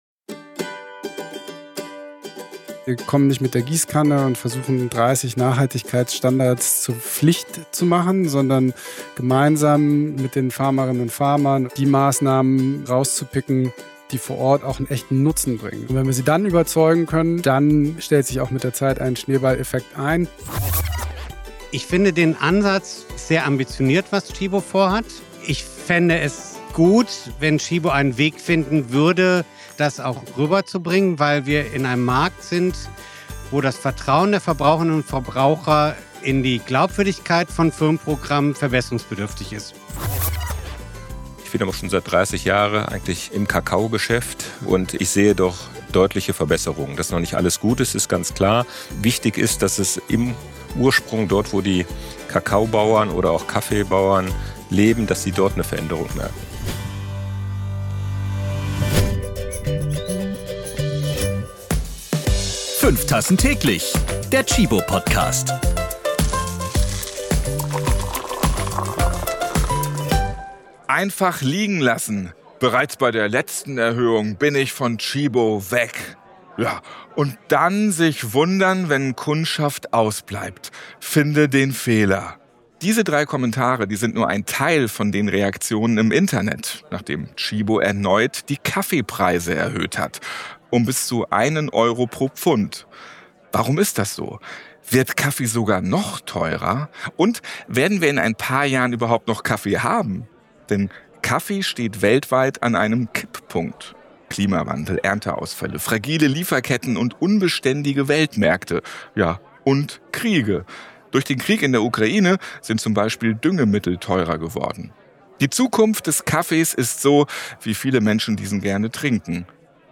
Gibt es morgen noch Kaffee? – Live vom KLARTEXT-Event in Berlin ~ 5 Tassen täglich – Kaffee & Nachhaltigkeit Podcast